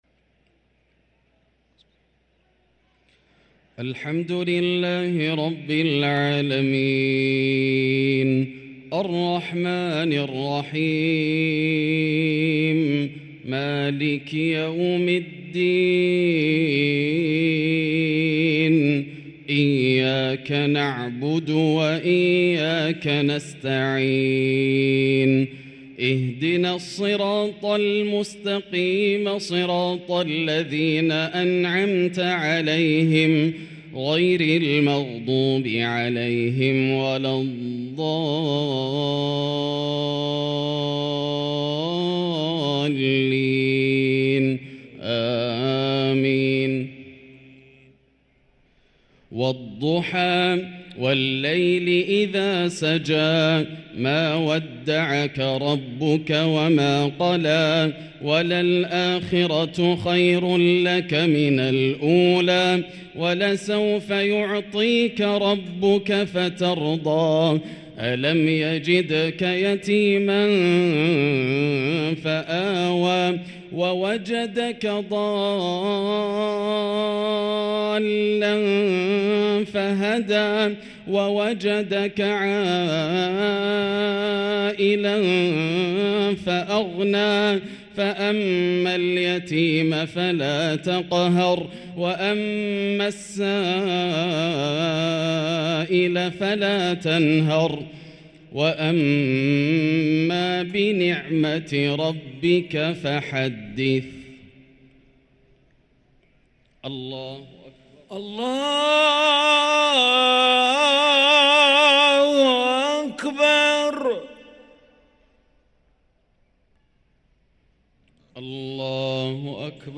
صلاة العشاء للقارئ ياسر الدوسري 25 رمضان 1444 هـ
تِلَاوَات الْحَرَمَيْن .